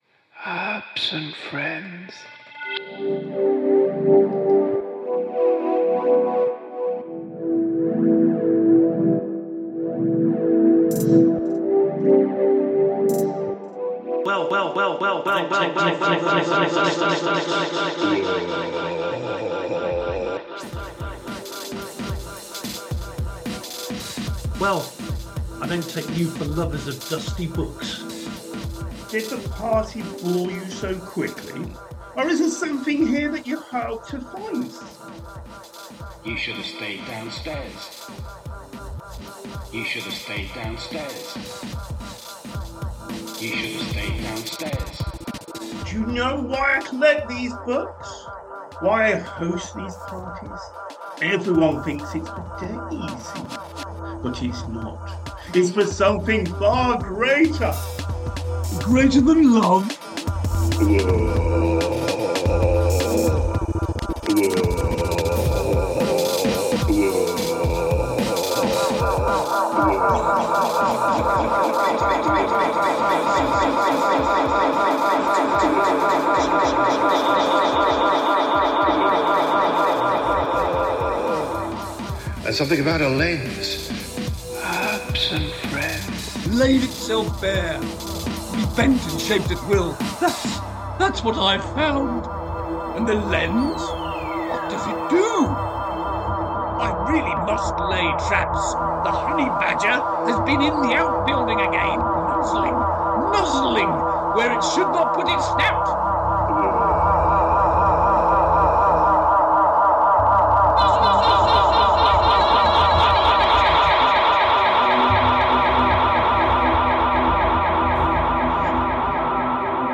They were there in spirit as they provided the sound effects for the alternate dimension that players travelled through during the fractured time.